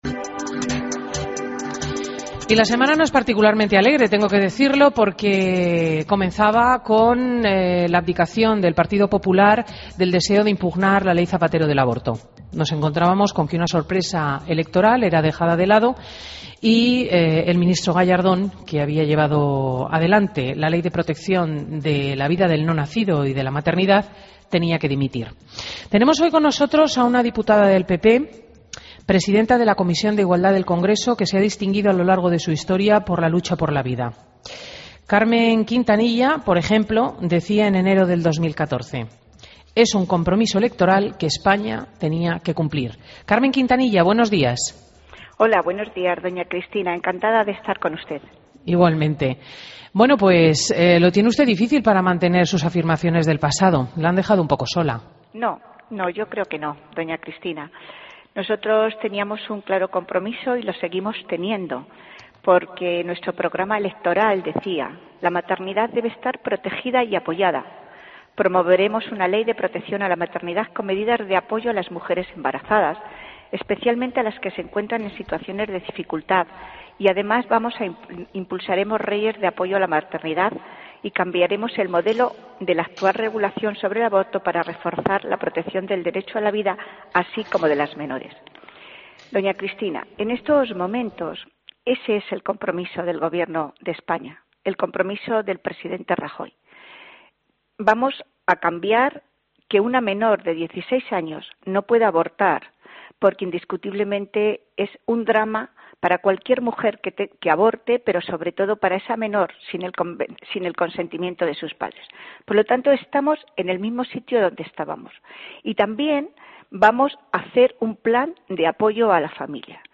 Entrevista a Carmen Quintanilla en Fin de Semana COPE